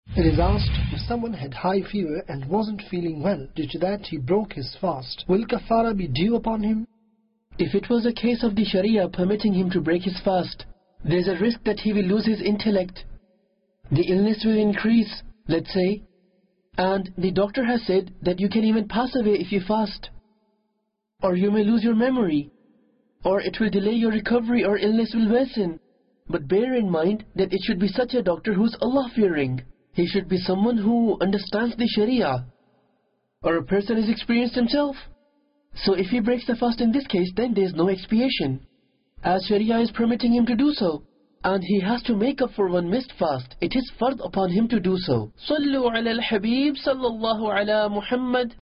What Is Shariah Ruling About Invalidating The Fast Due To High Fever? - English Dubbing Jul 2, 2021 MP3 MP4 MP3 Share What Is Shariah Ruling About Invalidating The Fast Due To High Fever?